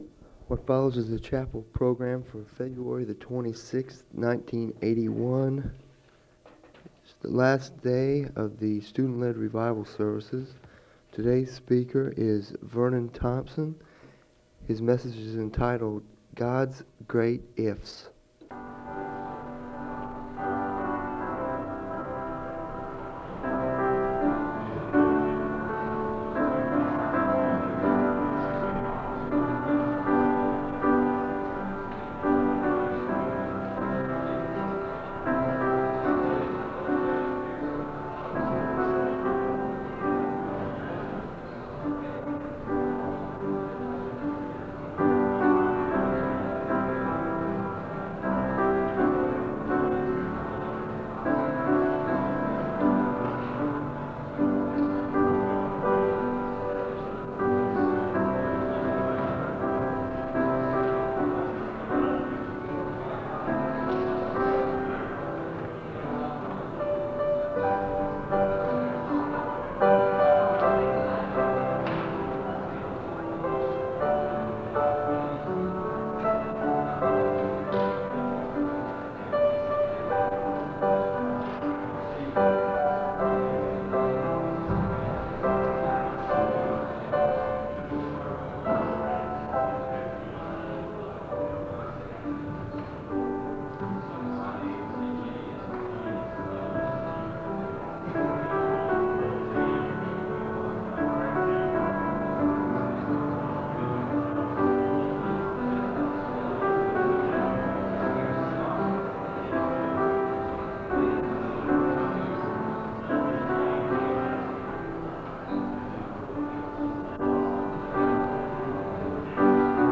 The service begins with piano music (00:00-03:32). The speaker reads from the Scriptures, and he gives a word of prayer (03:33-04:48).
The service ends with a word of prayer (27:32-28:44).
SEBTS Chapel and Special Event Recordings